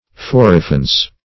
Forefence \Fore`fence"\, n. Defense in front.